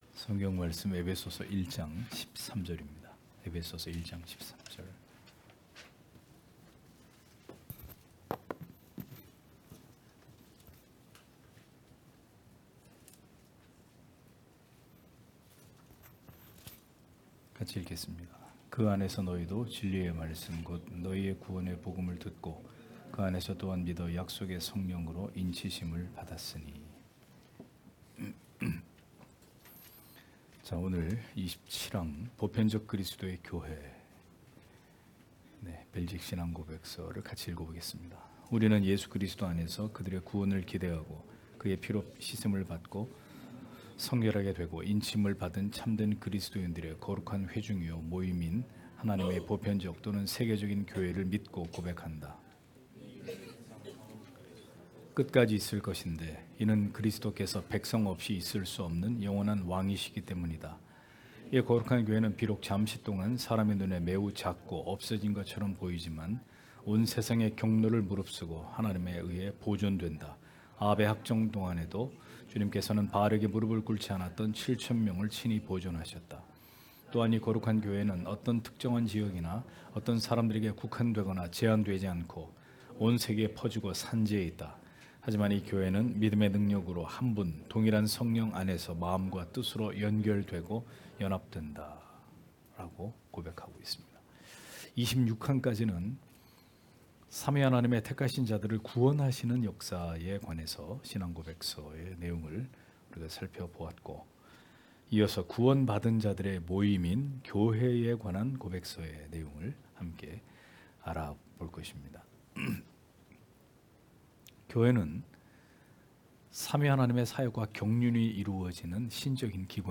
주일오후예배 - [벨직 신앙고백서 해설 31] 제27항 보편적 그리스도의 교회 (엡 1장13절)